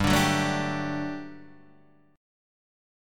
G Major 7th Suspended 2nd